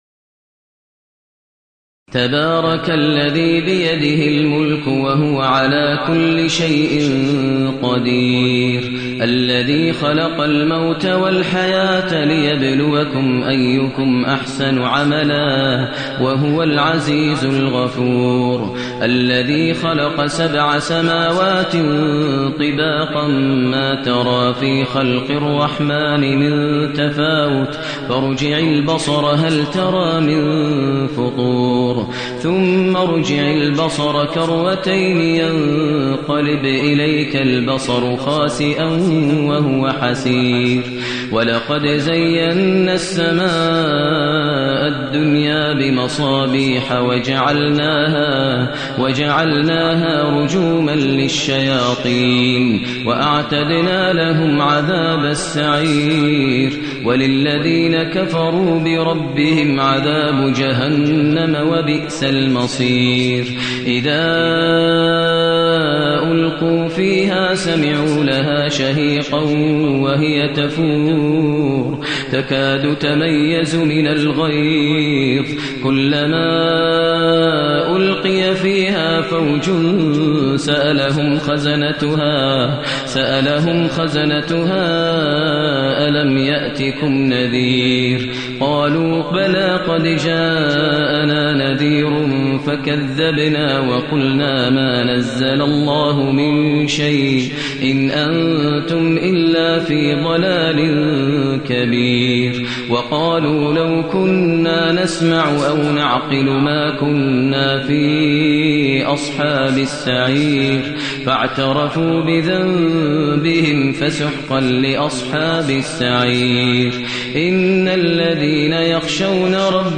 المكان: المسجد النبوي الشيخ: فضيلة الشيخ ماهر المعيقلي فضيلة الشيخ ماهر المعيقلي الملك